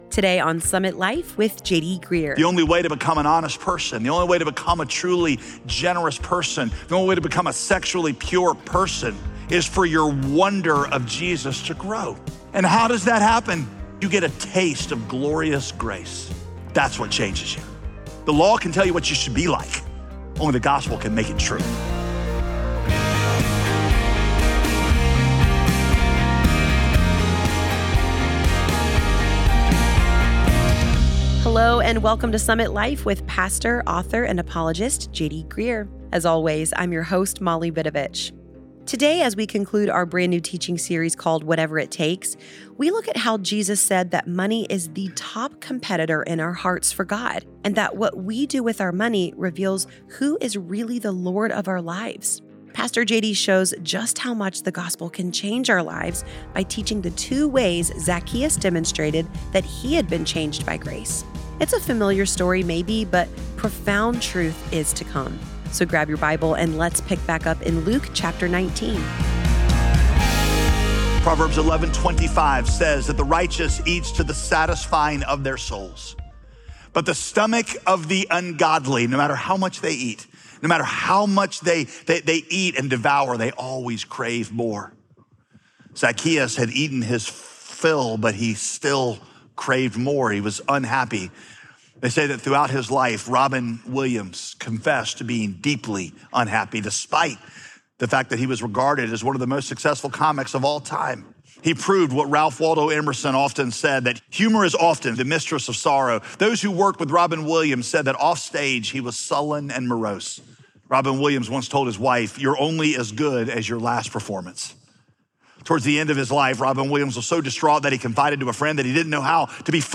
Jesus said that money is the top competitor in our hearts for God, and what we do with our money reveals who is really the Lord of our lives. In this message